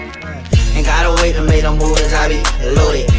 charging.mp3